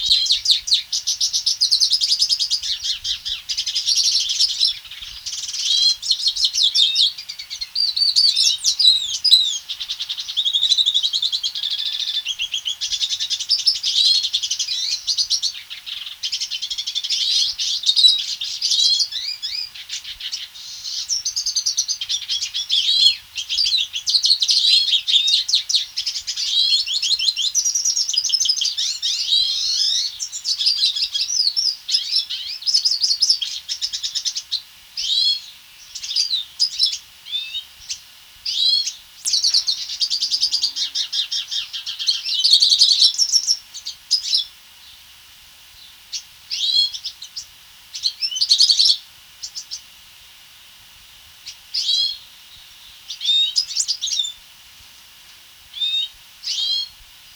Cardenalito,
Red Siskin
Carduelis cucullata
VOZ: El canto ha sido descrito como una mezcla de gorjeos y trinos; el llamado como un áspero "yut-yut".